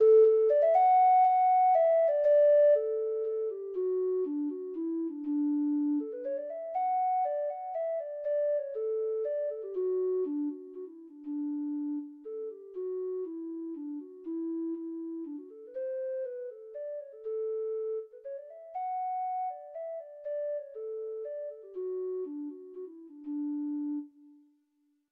World Europe Ireland I Will Drink No More (Irish Folk Song) (Ireland)
Free Sheet music for Treble Clef Instrument
Irish